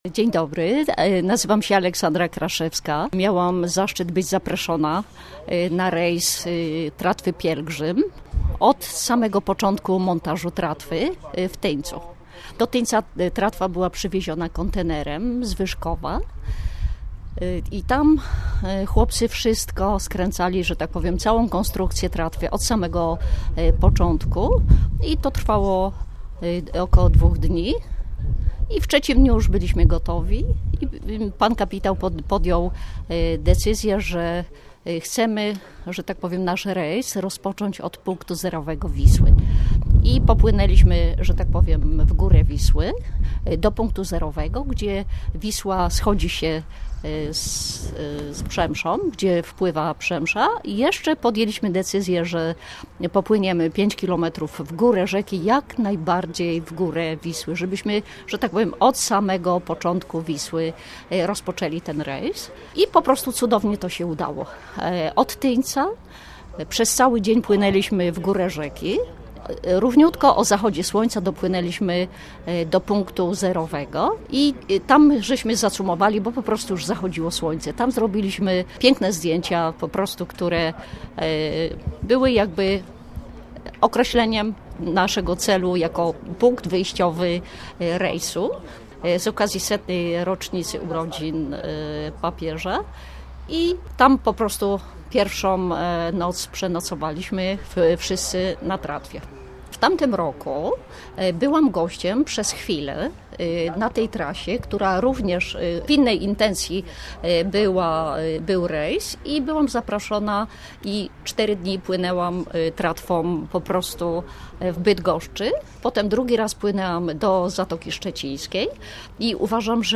W starym porcie w Sandomierzu zacumowała tratwa 'Pielgrzym”.